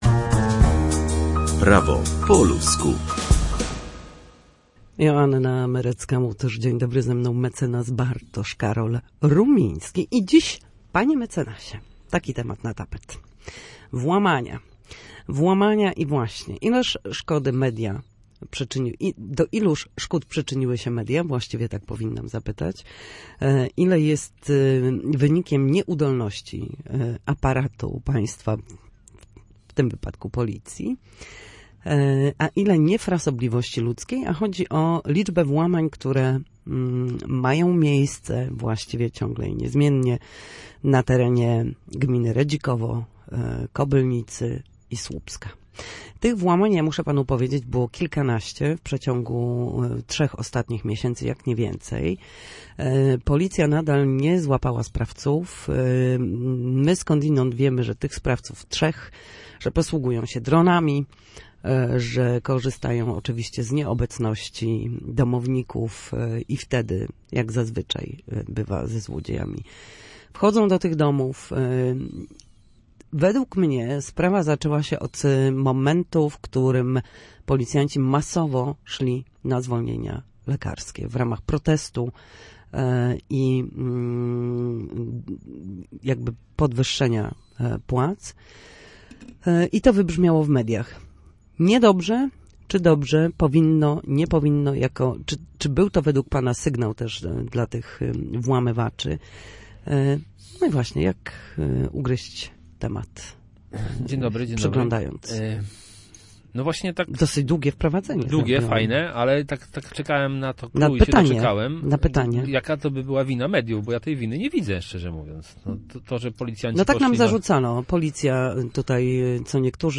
W każdy wtorek o godzinie 13:40 na antenie Studia Słupsk przybliżamy państwu meandry prawa. Nasi goście, prawnicy, odpowiadać będą na jedno pytanie dotyczące zachowania w sądzie czy podstawowych zagadnień prawniczych.